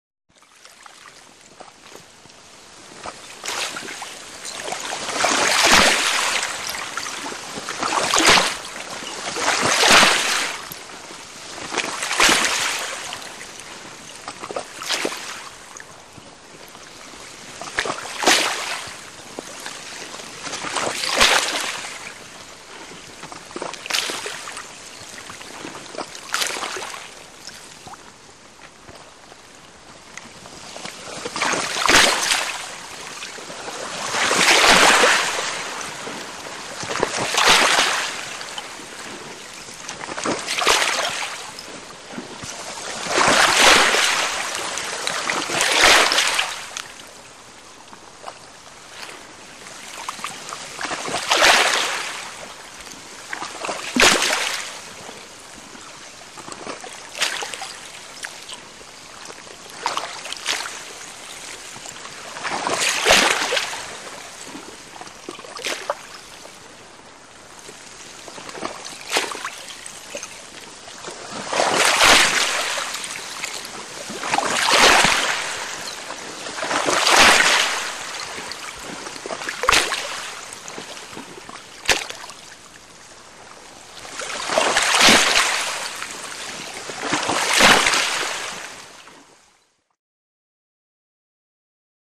Heavy Water Laps In Icy Lake, Crystals, Close Point of View.